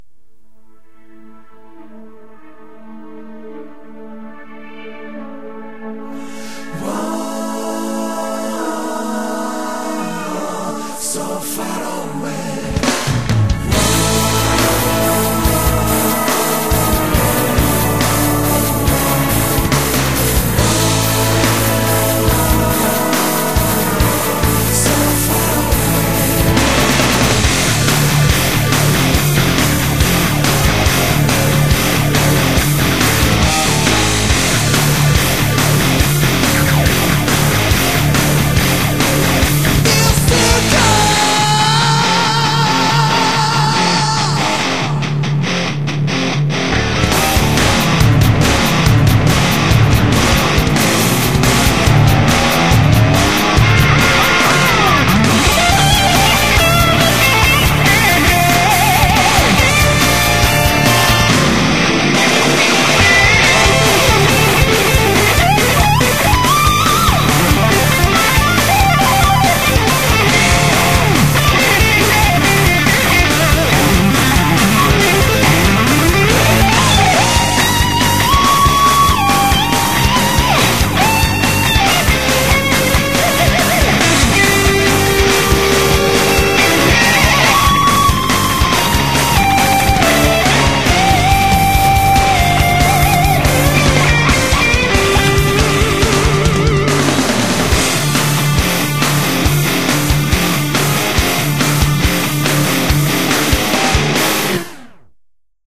BPM70-140
Audio QualityPerfect (High Quality)